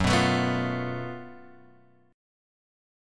F# Minor Major 7th Double Flat 5th